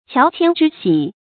乔迁之喜 qiáo qiān zhī xǐ 成语解释 乔：乔木；枝干高大的树木；乔迁：指鸟儿飞离幽谷；迁移到高大的树木上去。
成语繁体 喬遷之喜 成语简拼 qqzx 成语注音 ㄑㄧㄠˊ ㄑㄧㄢ ㄓㄧ ㄒㄧˇ 常用程度 常用成语 感情色彩 中性成语 成语用法 偏正式；作宾语；祝贺别人迁居或升官的话 成语结构 偏正式成语 产生年代 古代成语 成语正音 之，不能读作“zī”。